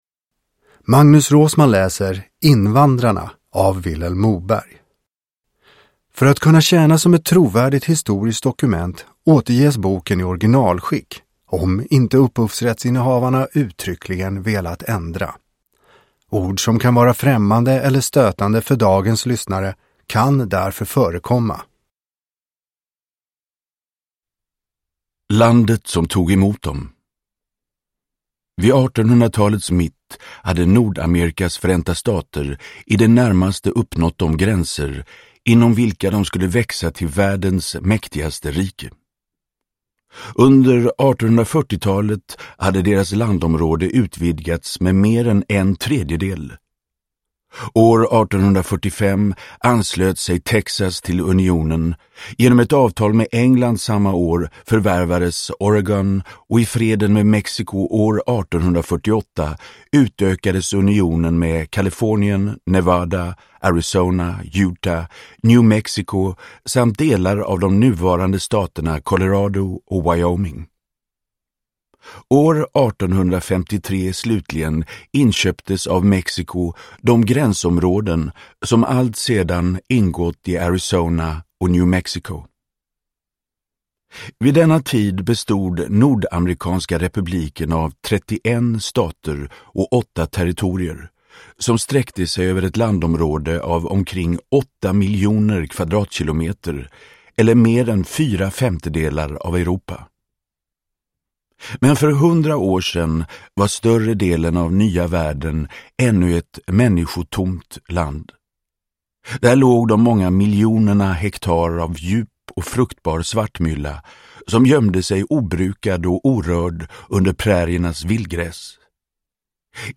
Invandrarna – Ljudbok – Laddas ner
Uppläsare: Magnus Roosmann